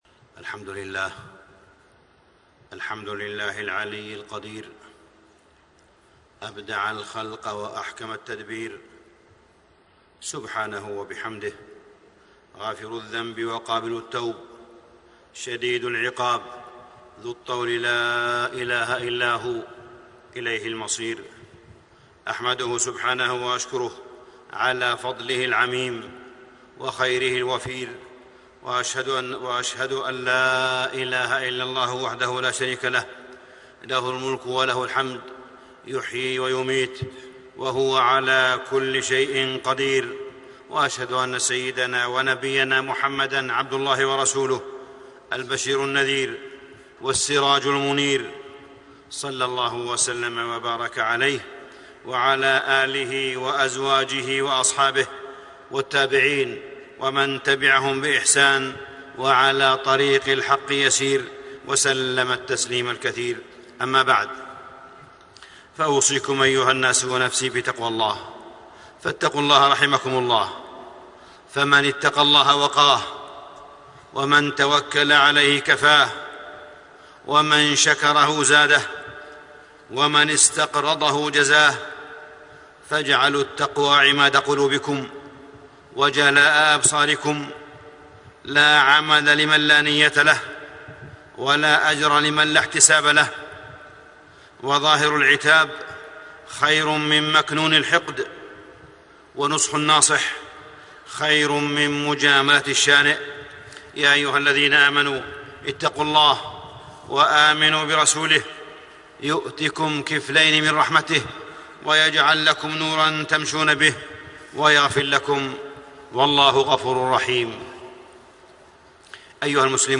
تاريخ النشر ١٢ شعبان ١٤٣٤ هـ المكان: المسجد الحرام الشيخ: معالي الشيخ أ.د. صالح بن عبدالله بن حميد معالي الشيخ أ.د. صالح بن عبدالله بن حميد التحذير من الإسراف والتبذير